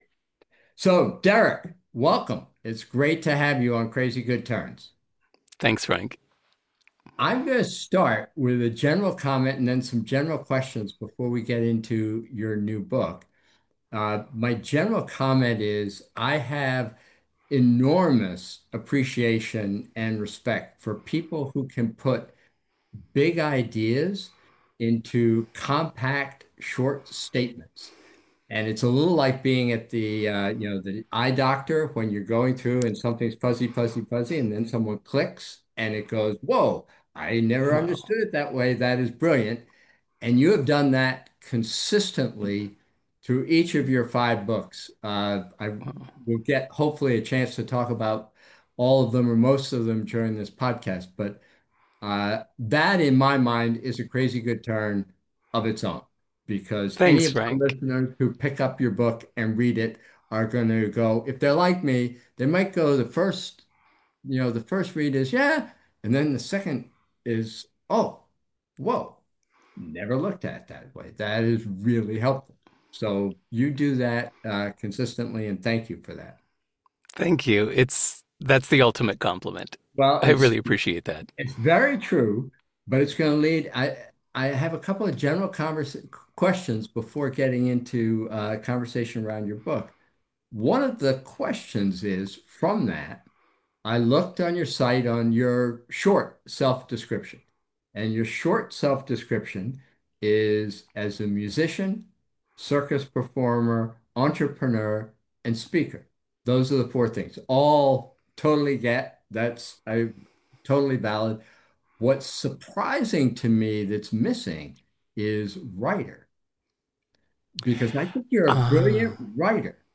Crazy Good Turns interview by Frank Blake